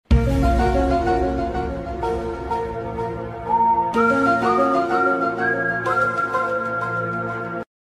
Thể loại: Âm thanh meme Việt Nam